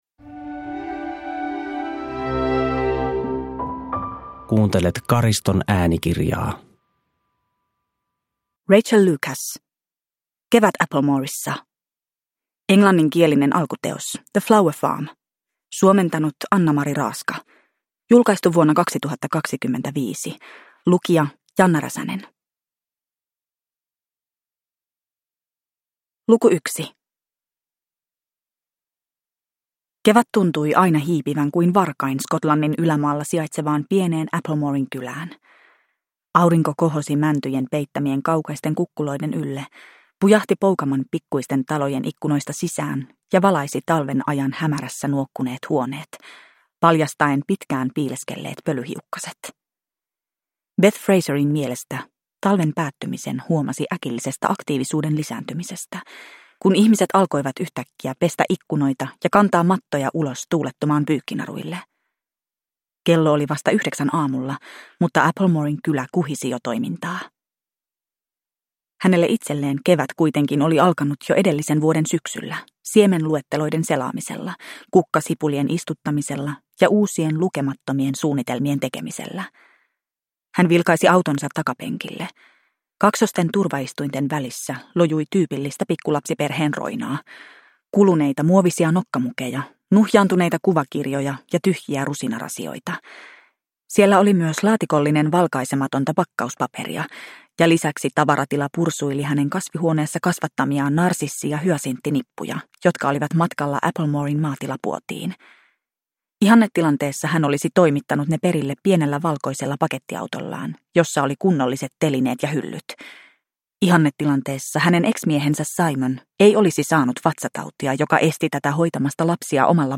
Kevät Applemoressa (ljudbok) av Rachael Lucas